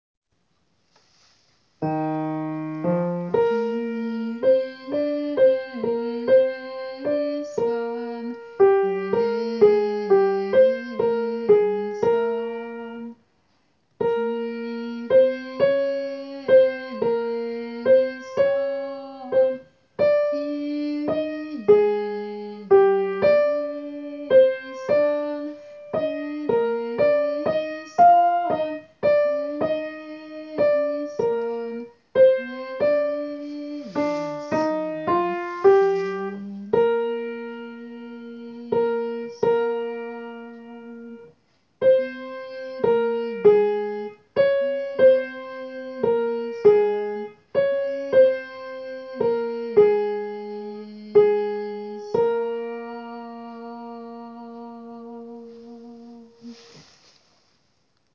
Tenor
gounod.kyrie_.suite_.tenor_.wav